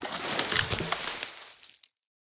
tree-leaves-4.ogg